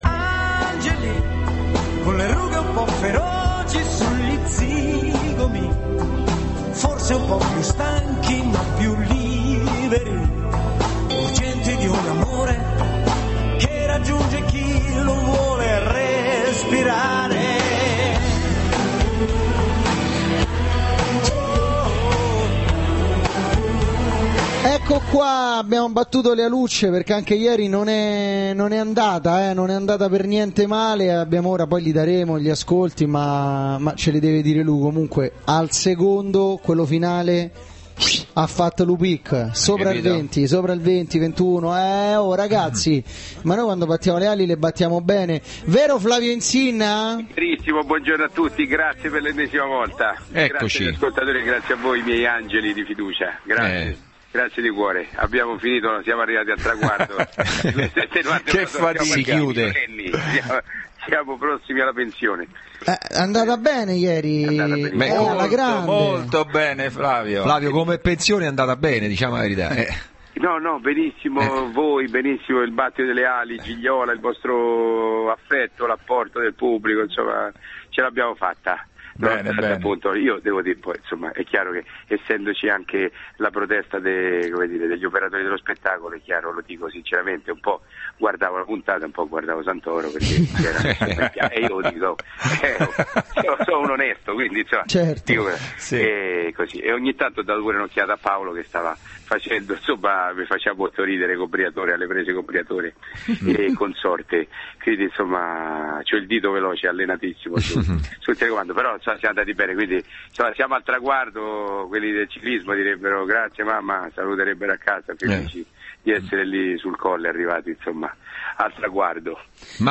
Intervento telefonico Flavio Insinna del 19/11/2010